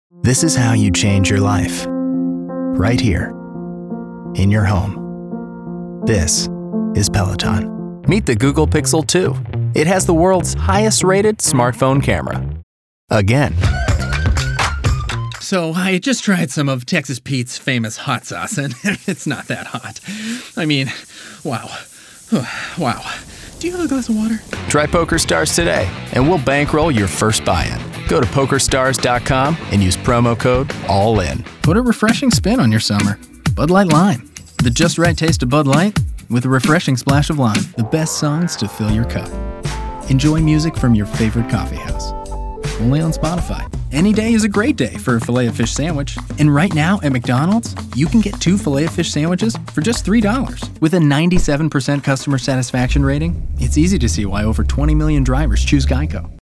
englisch (us)
Sprechprobe: Werbung (Muttersprache):